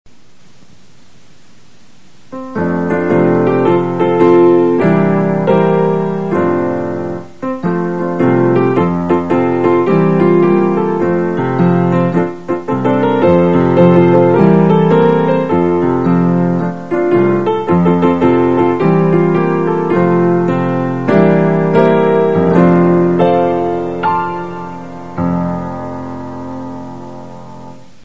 Click the next link to download the audio file of the song for each verse with lyrics, or click the third link in each box for the instrumental music alone.